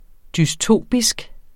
Udtale [ dysˈtoˀbisg ]